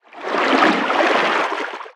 Sfx_creature_featherfish_swim_slow_02.ogg